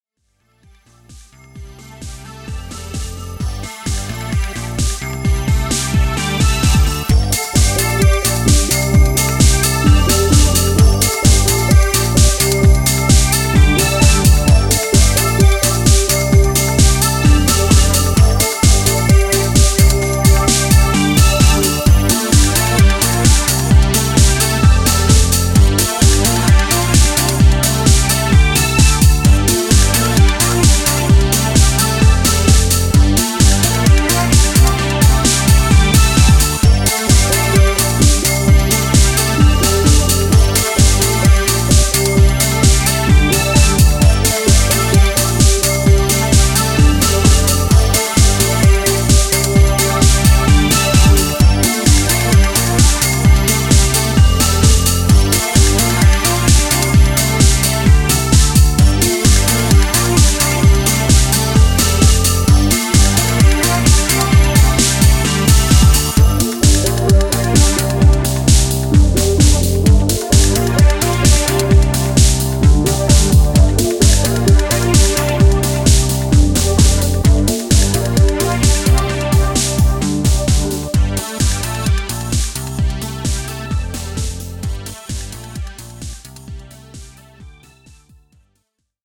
ニュースクールな地下テック・ハウスを披露しています。